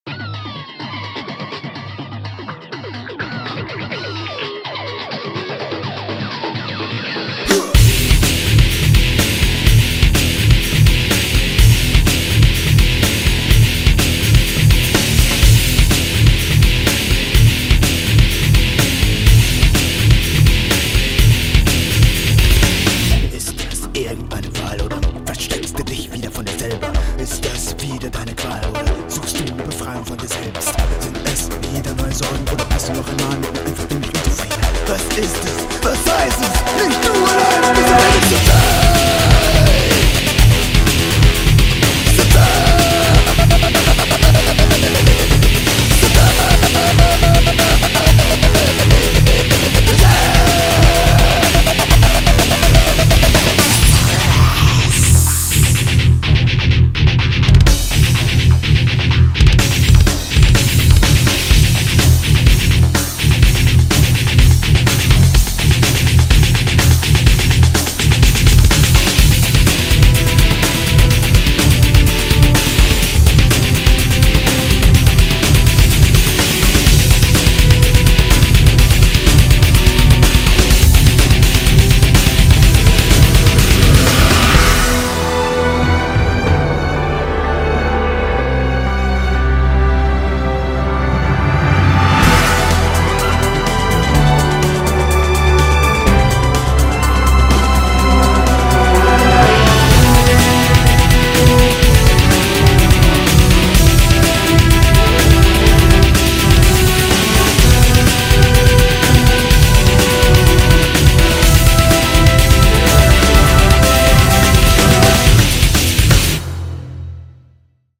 BPM63-125
Audio QualityPerfect (High Quality)
A Mixture hard rock remix